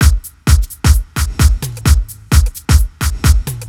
Downtown House/Loops/Drum Loops 130bpm